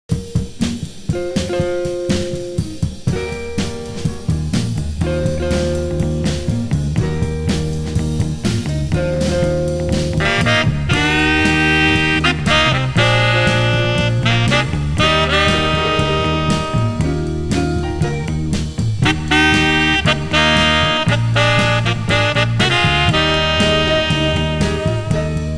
(税込￥1980)   FUNKY